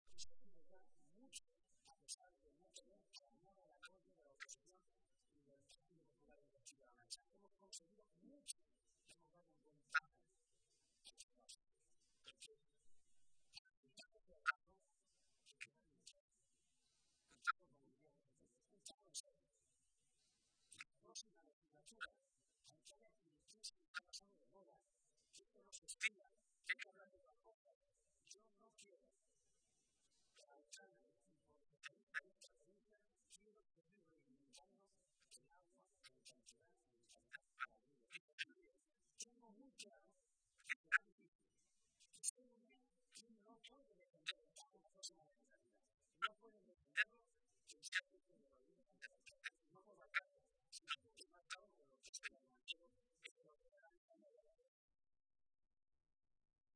Barreda a su llegada a la comida de Navidad del PSOE de Toledo.